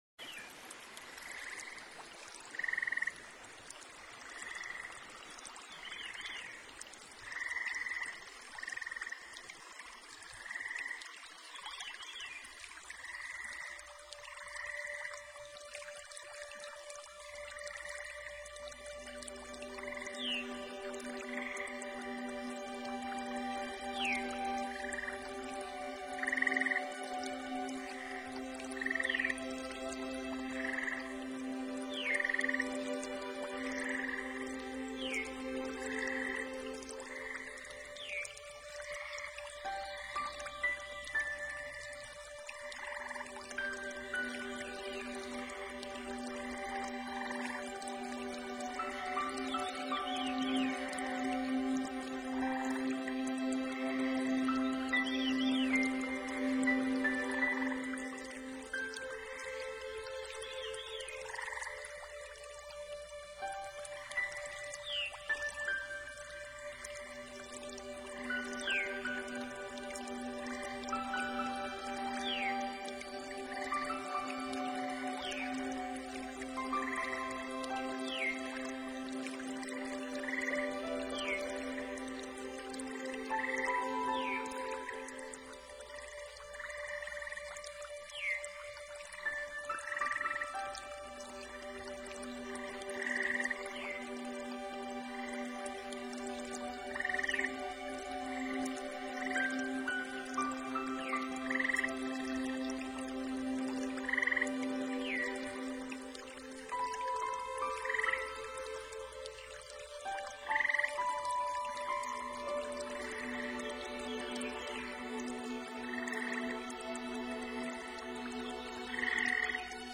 沁凉清澈的潺潺流水，倾泻而飞的白色瀑布，晶莹剔透的雨滴…融入如诗歌般的曲韵中。